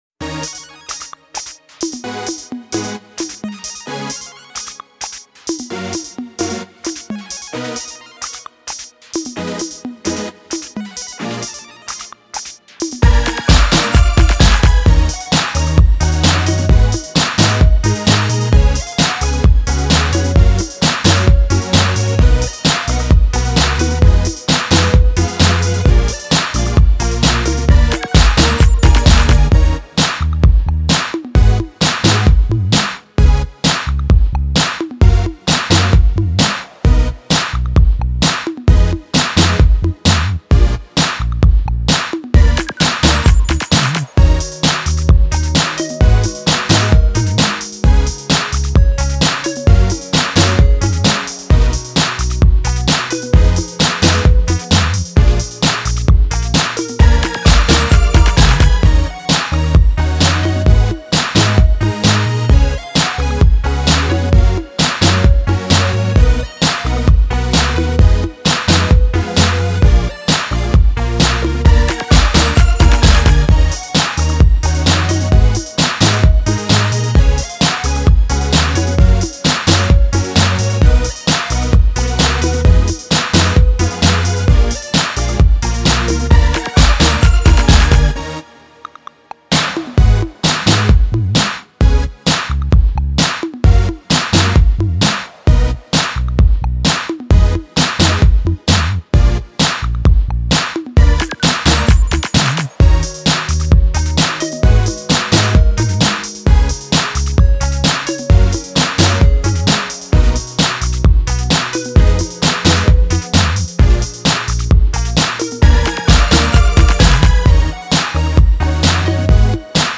注入流行元素，呈现出不一样的感觉。
韩国组合歌手